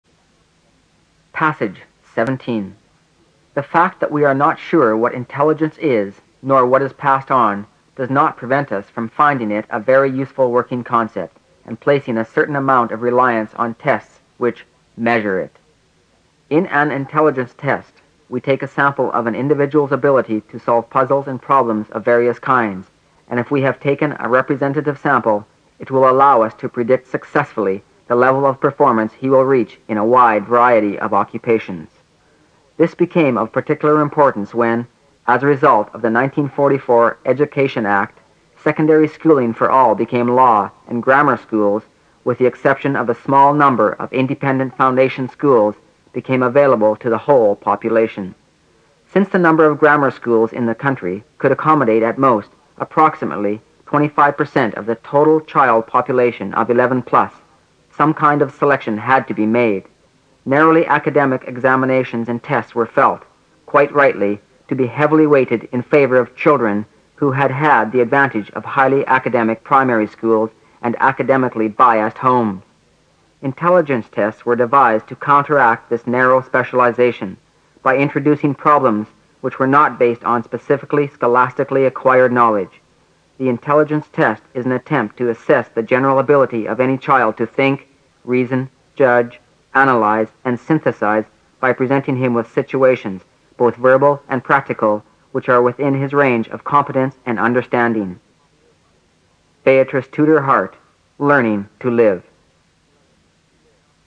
新概念英语85年上外美音版第四册 第17课 听力文件下载—在线英语听力室